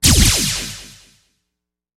SFX激光连续发射动画UI交互游戏音效下载
SFX音效